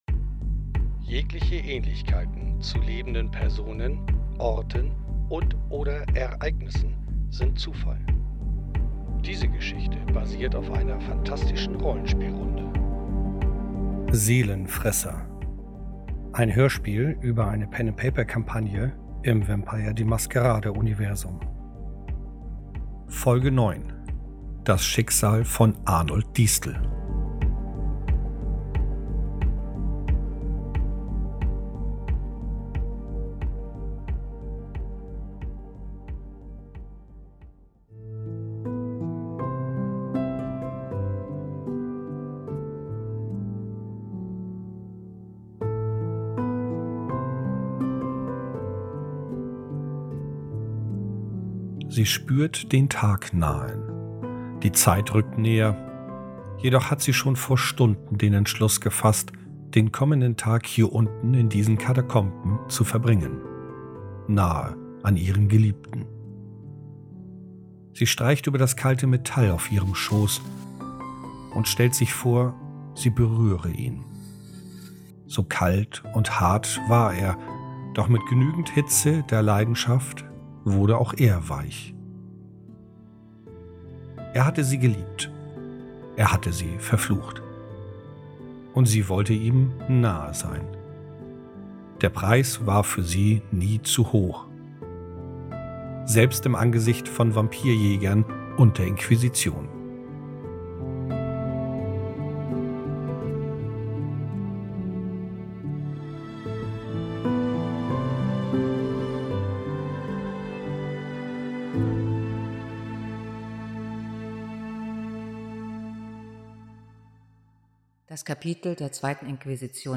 Hierbei handelt es sich um eine Rollenspielkampagne im Vampire: Die Maskerade Universum. Die Gespräche der Spielerin und Spieler wurden während der Sitzung aufgenommen und zu einem Hörspiel verarbeitet.
seelenfresser-hoerspiel